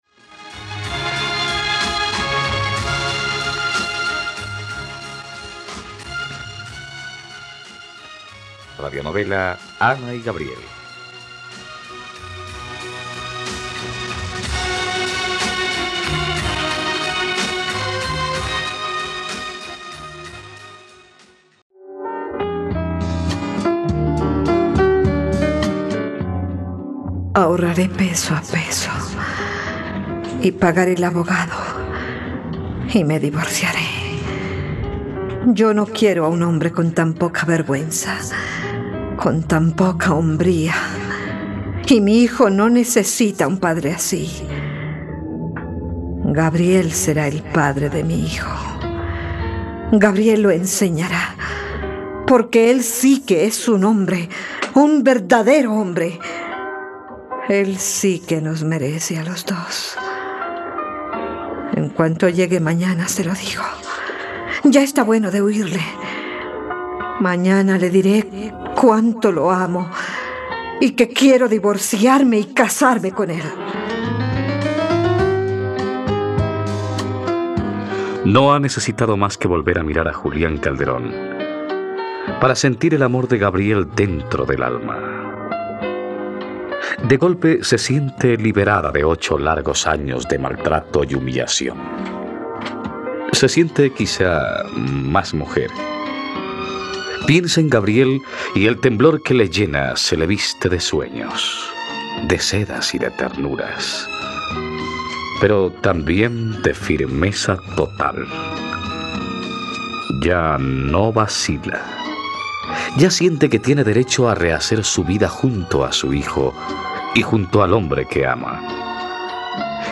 Ana y Gabriel - Radionovela, capítulo 45 | RTVCPlay